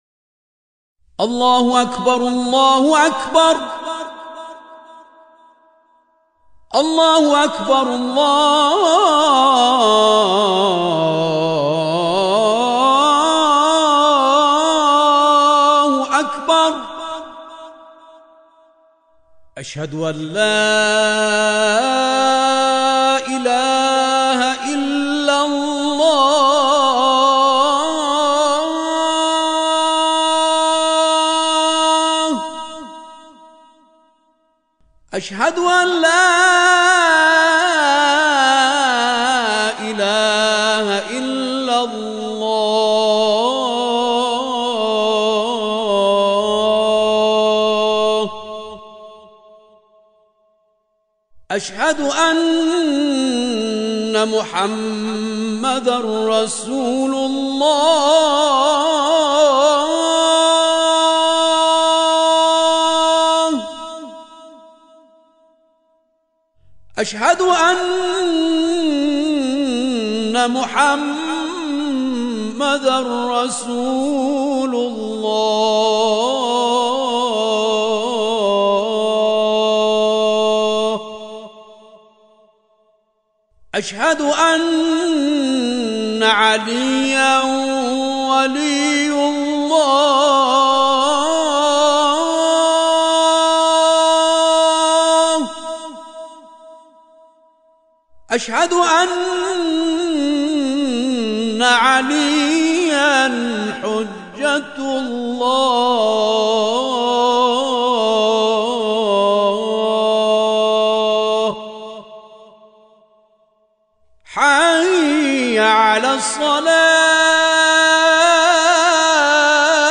سال‌ها ممارست برای ارائه اذانی ماندگار / اجرای 2 اذان در مقام‌های «رست» و «سه‌گاه»
اذان